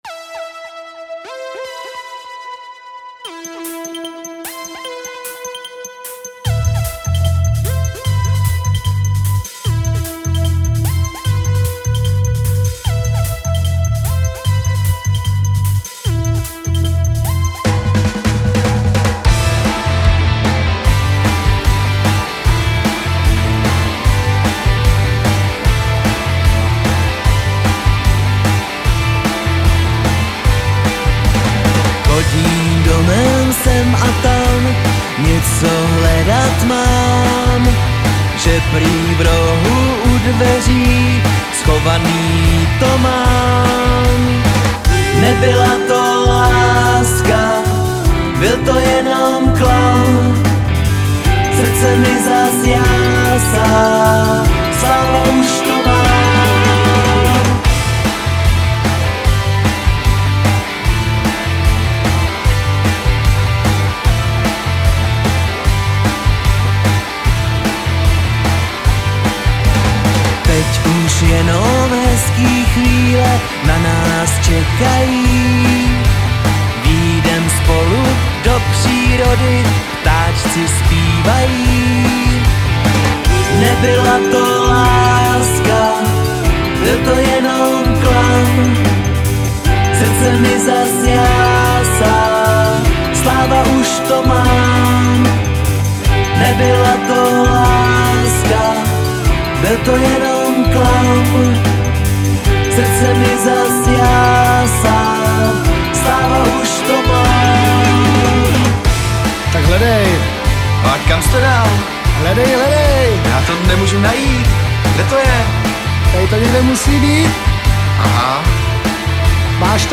Žánr: Pop
veselý nekomplikovaný pop rock